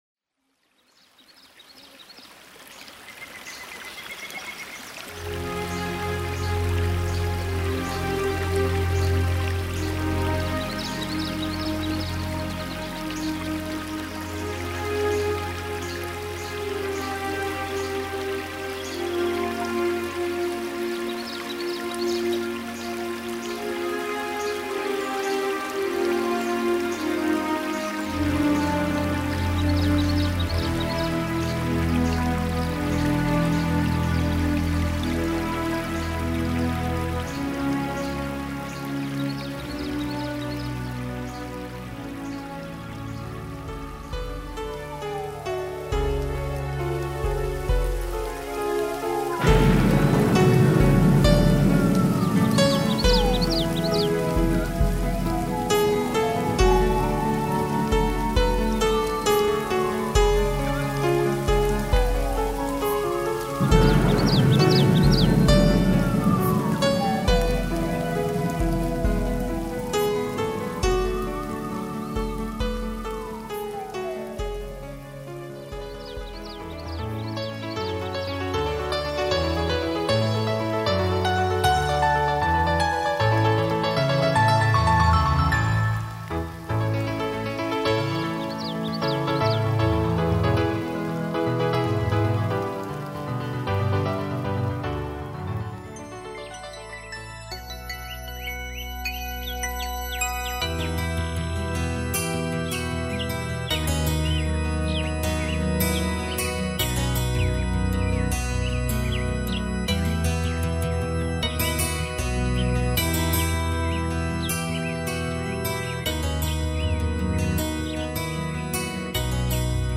szintetizátoros betéteket írtam